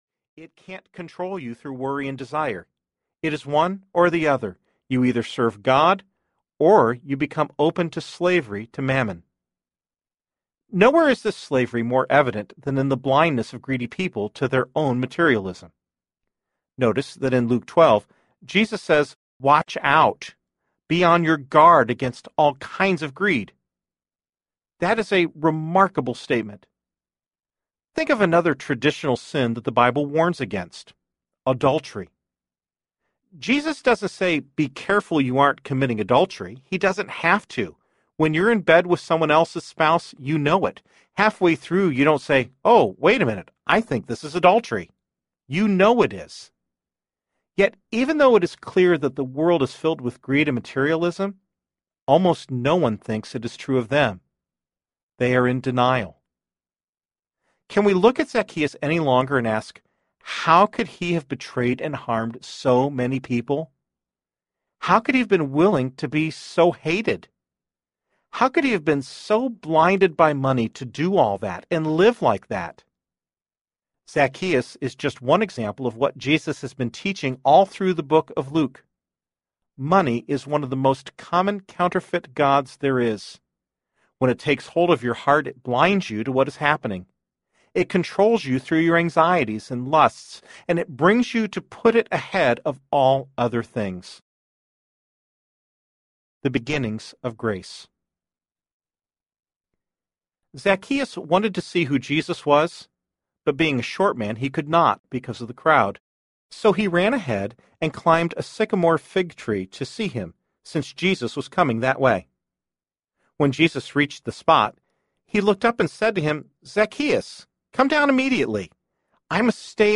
Counterfeit Gods Audiobook
Narrator
Counterfeit Gods Brilliance Audio SAMPLE.mp3